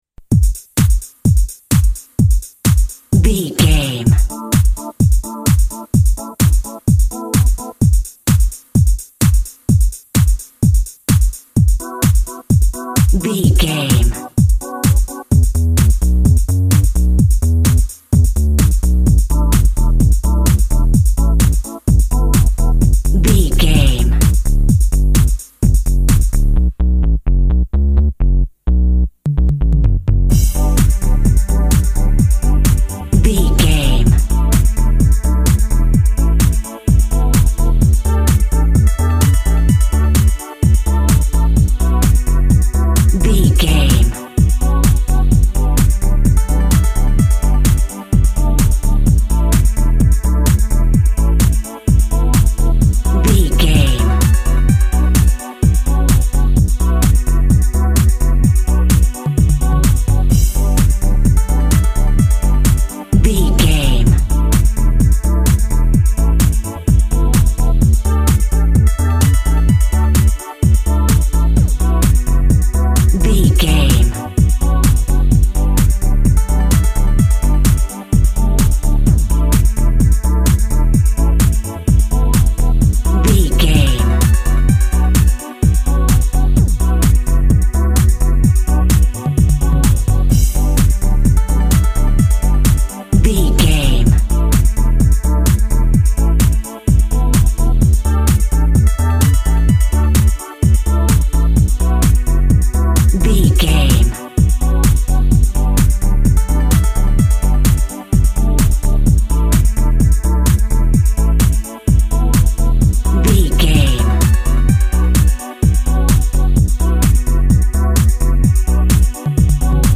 Organizing Electronic Music.
Ionian/Major
D
groovy
uplifting
futuristic
driving
energetic
repetitive
drum machine
techno
trance
synth lead
synth bass
electronic drums
Synth Pads